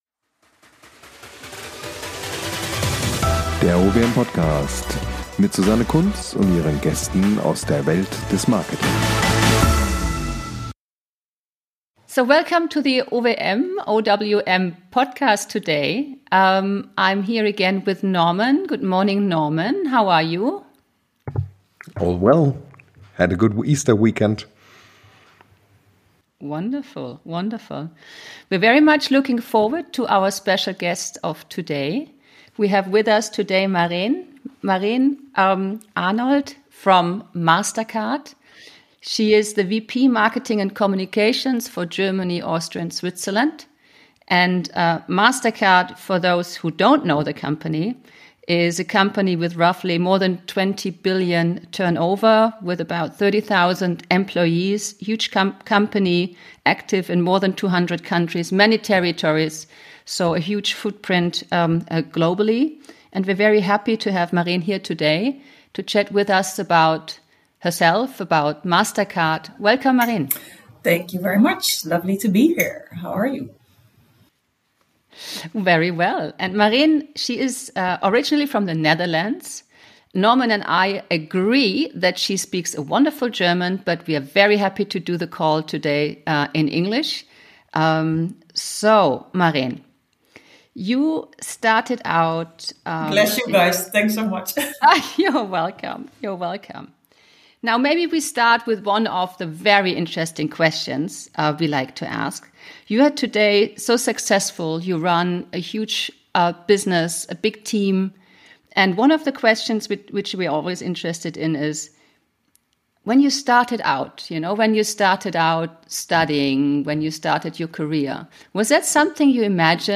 im Gesrpäch mit der OWM ~ Der OWM Podcast